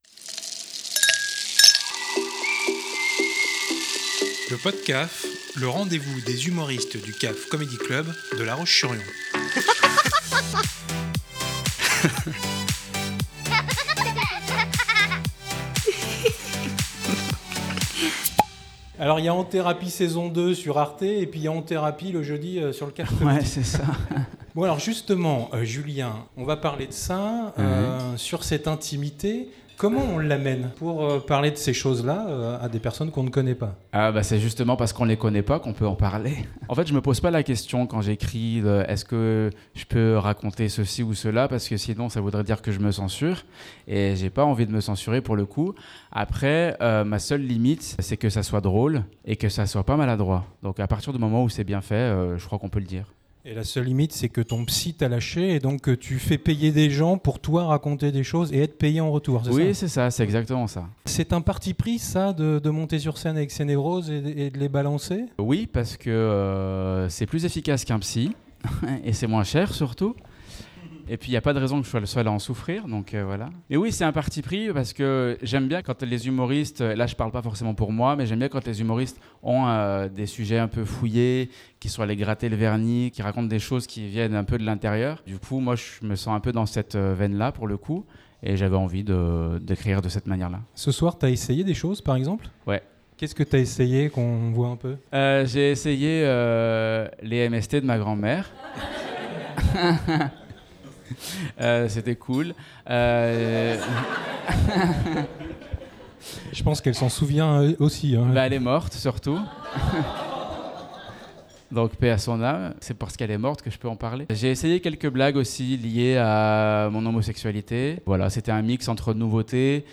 Genres: Comedy, Stand-Up
L'interview « Coucou »